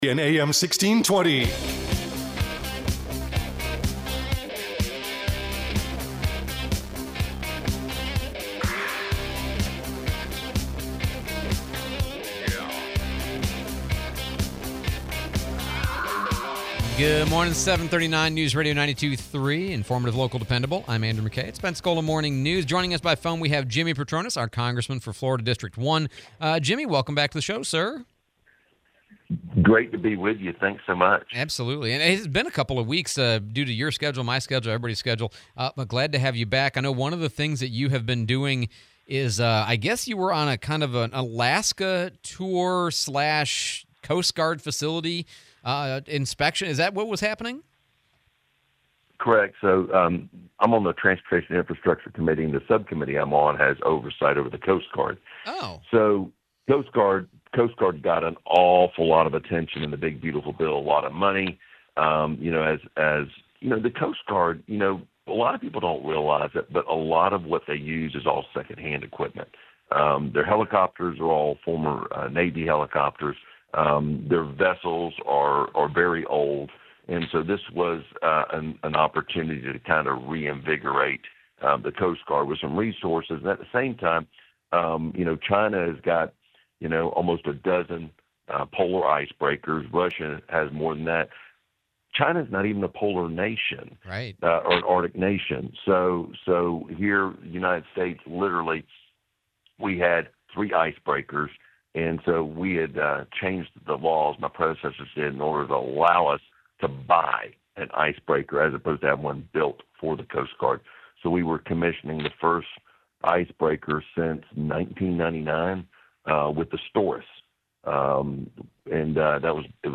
08/18/25 Congressman Jimmy Patronis interview